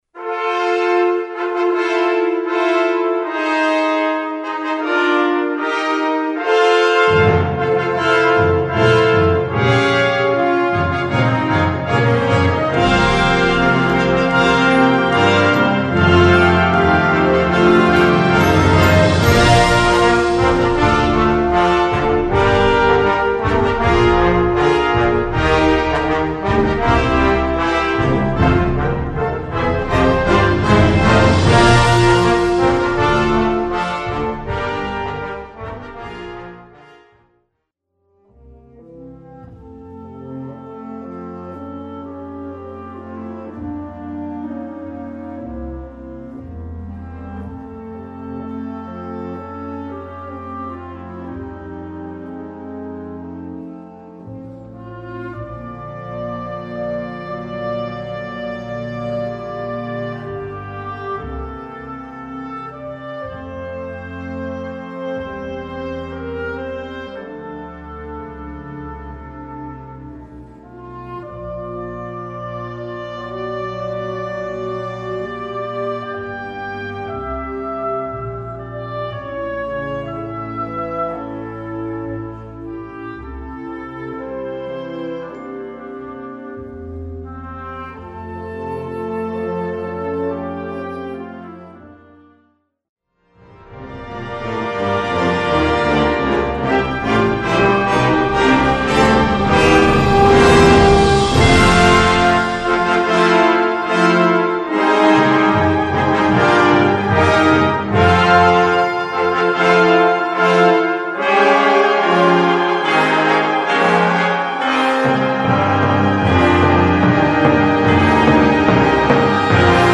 Unterkategorie Ouvertüre (Originalkomposition)
Besetzung Ha (Blasorchester)
eine klangvolle Ouvertüre für Blasorchester